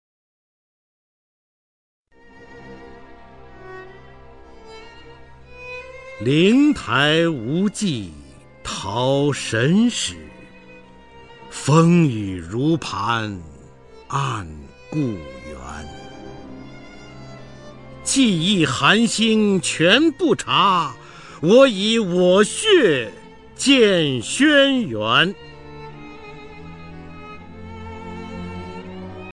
方明朗诵：《自题小像》(鲁迅) 鲁迅 名家朗诵欣赏方明 语文PLUS